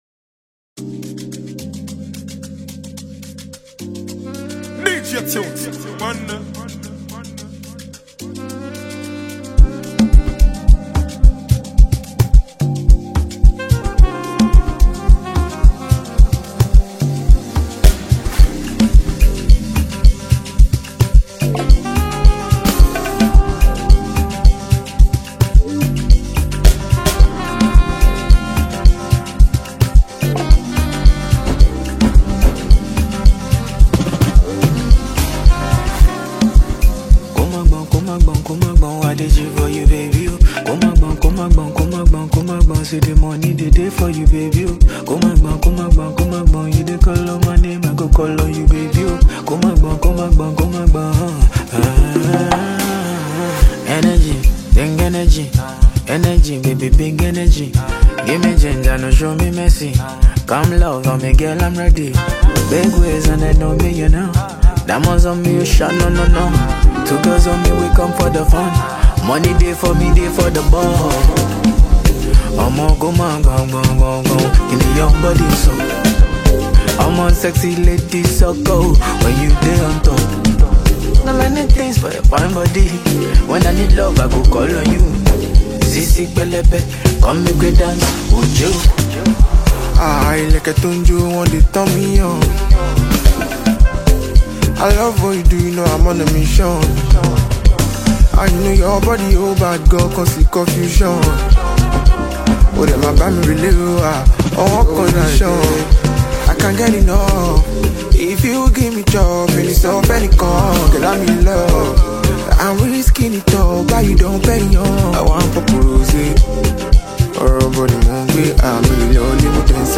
lays down a foundation of breezy log drums and warm keys
feather-light harmonies